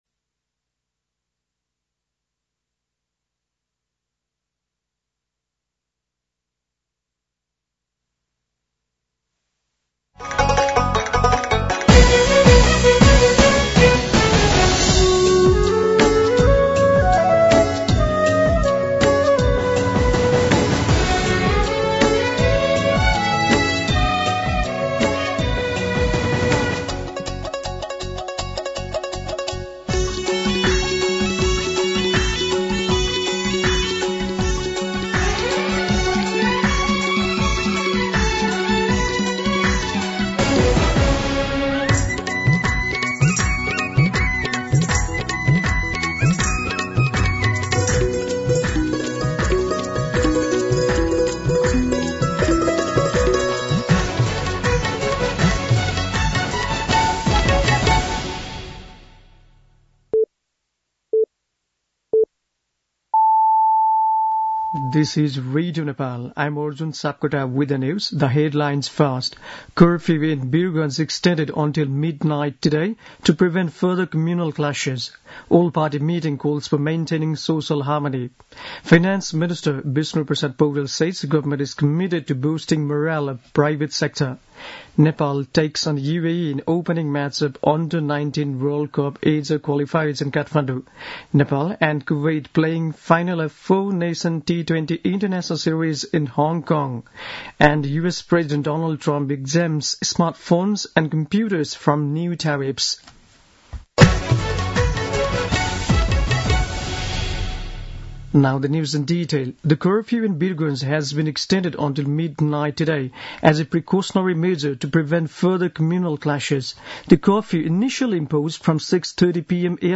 दिउँसो २ बजेको अङ्ग्रेजी समाचार : ३१ चैत , २०८१
2pm-English-News-1.mp3